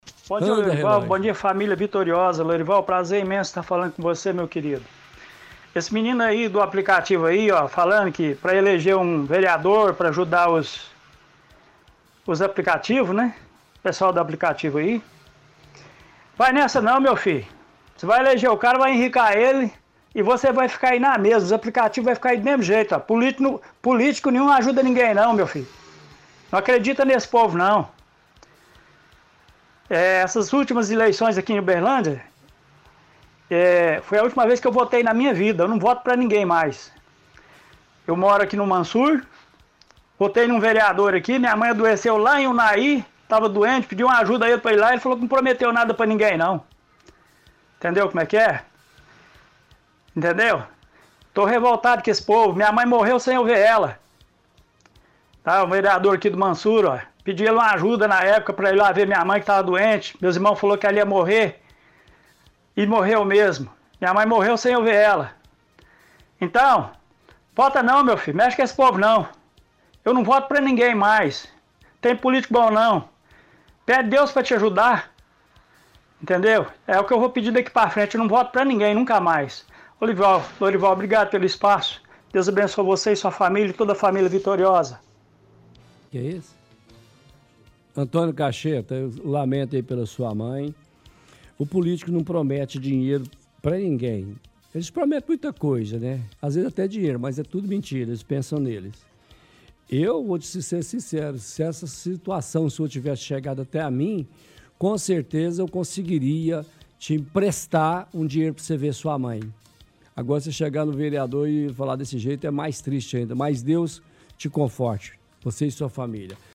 -Ouvinte diz que político nenhum ajuda ninguém, diz que nas últimas eleições aqui em Uberlândia foi a última vez que votou em sua vida e que não vota para ninguém mais.